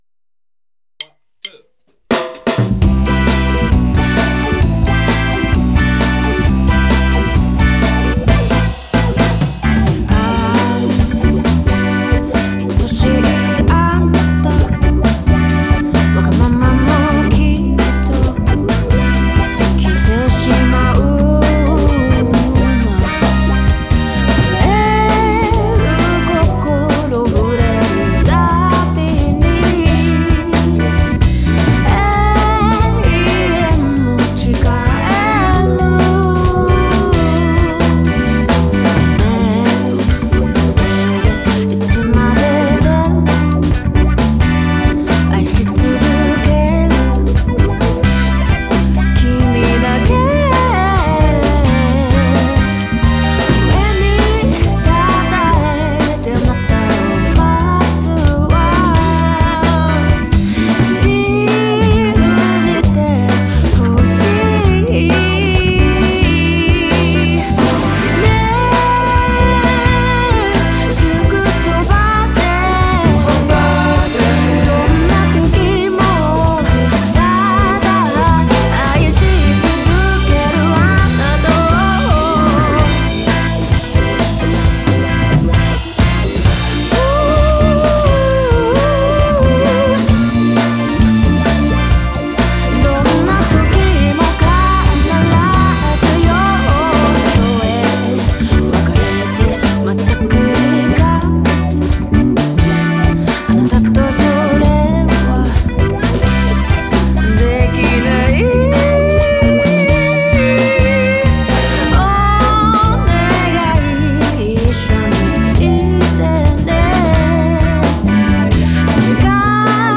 ジャンル(スタイル) JAPANESE / DISCO / SOUL / FUNK